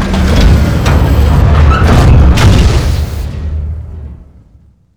FinishDocking.wav